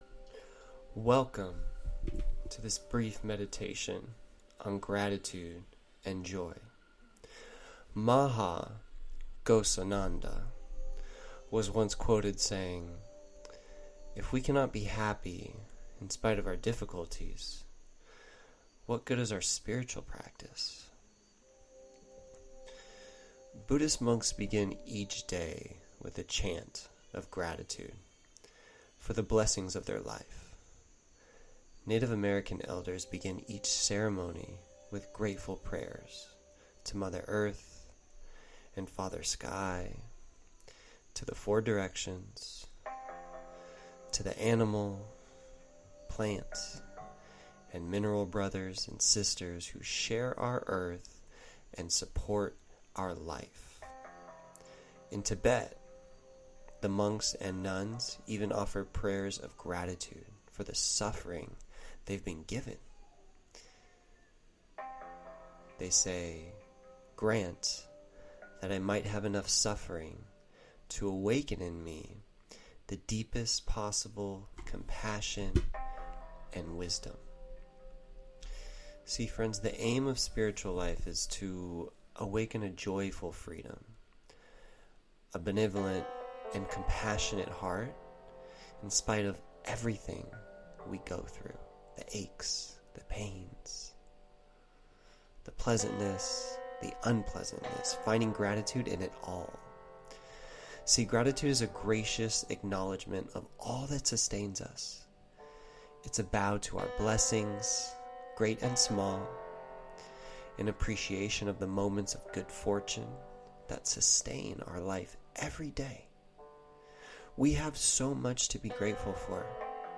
A Meditation on Gratitude and Joy
mp3_Alchemy-SD_A-Meditation-on-Gratitude-and-Joy.mp3